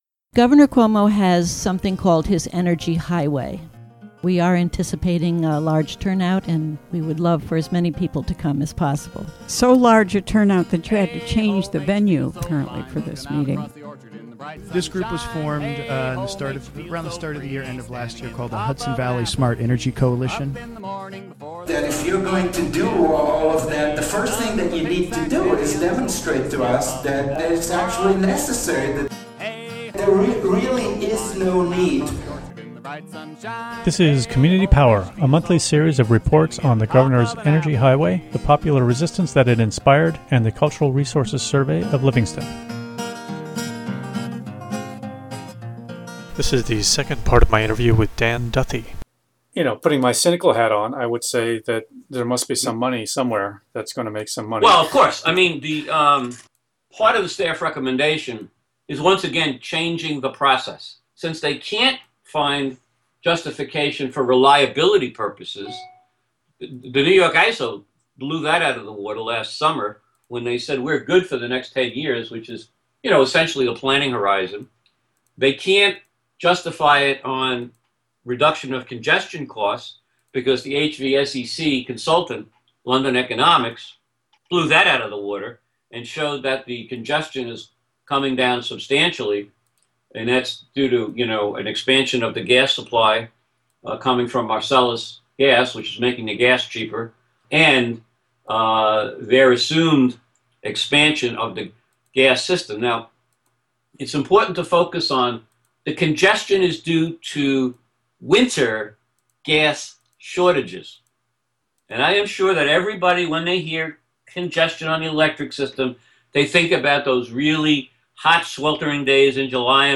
The interview is part of "Community Power," a year-long series of conversations about the New York Energy Highway Blueprint, the local response to that initiative, and the Cultural Resources Survey of Livingston.